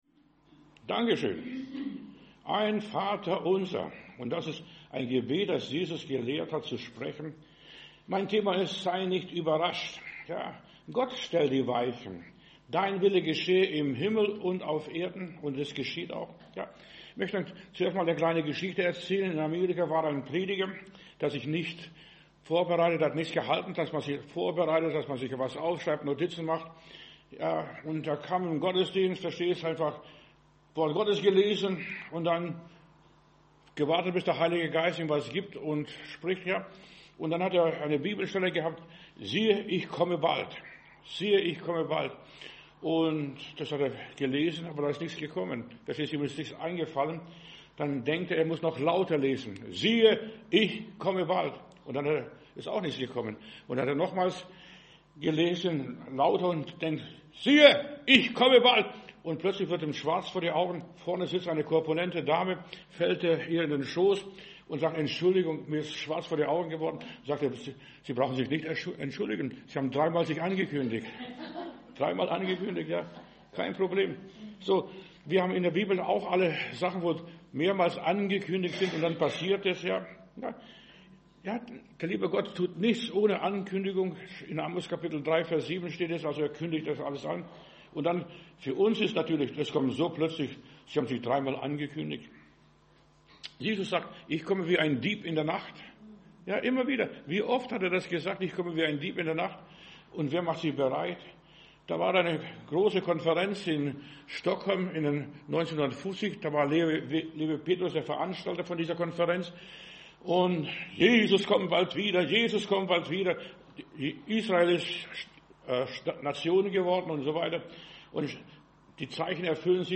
Predigt herunterladen: Audio 2025-01-19 Sei nicht überrascht Video Sei nicht überrascht